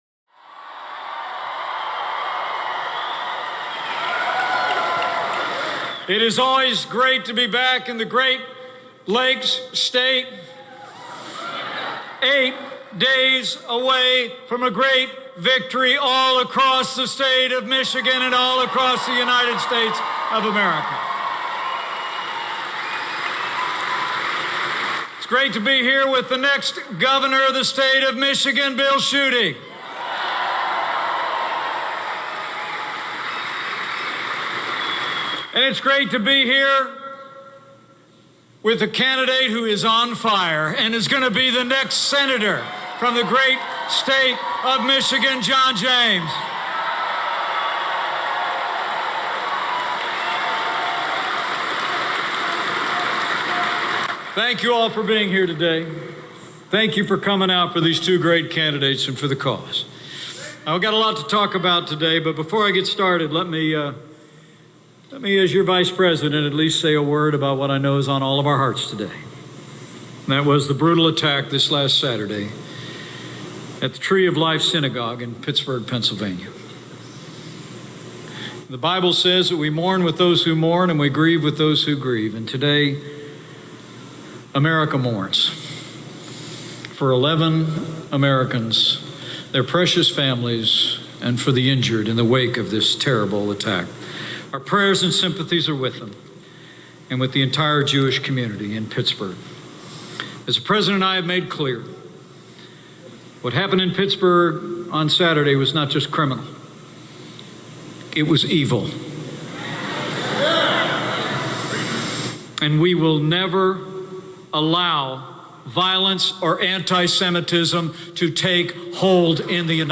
U.S. Vice President Mike Pence speaks at a campaign rally for Michigan Republican gubernatorial candidate Bill Schuette. Pence praises President Donald Trump and touts his administration's successes in the economy, employment and world trade. Pence also talks about the Republican candidates running for state and national office and why they should be elected. Held in Grand Rapids, Michigan.